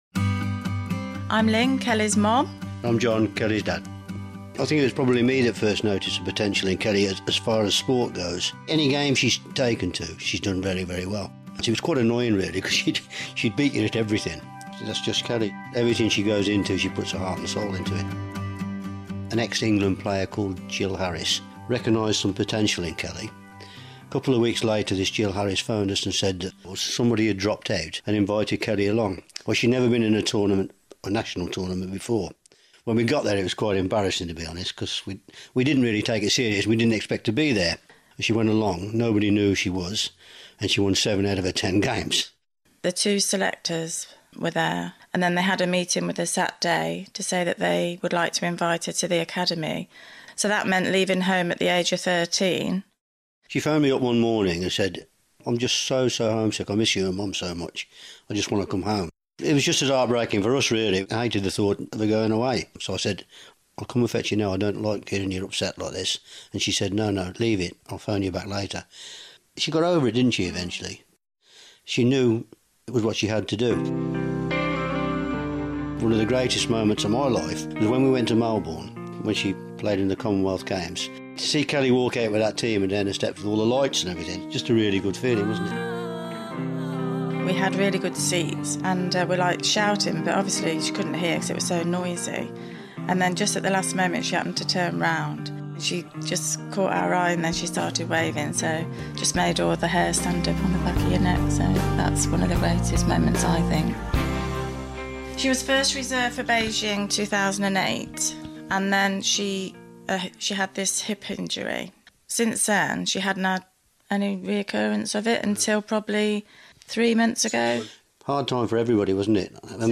Mum & Dad talk to BBC Coventry & Warwickshire